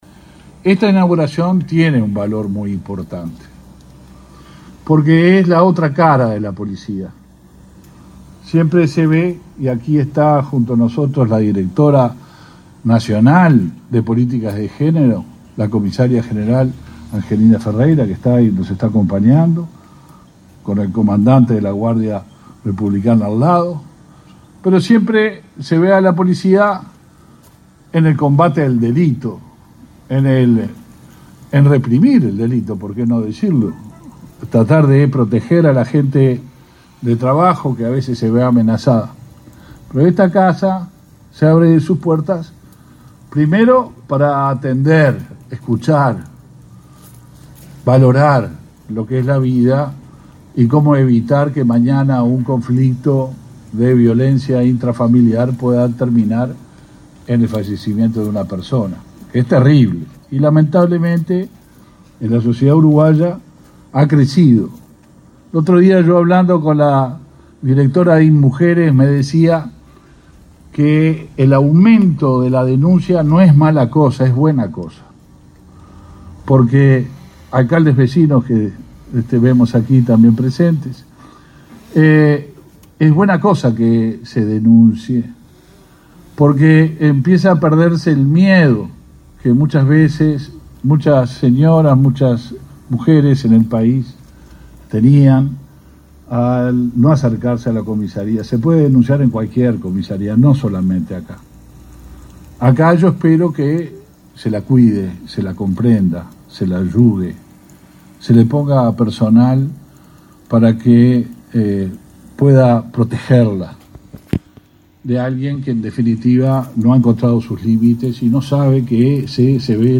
Palabras del ministro del Interior, Luis Alberto Heber
El ministro del Interior, Luis Alberto Heber, participó el martes 28 del lanzamiento del programa Verano Azul, en Atlántida, y de la inauguración de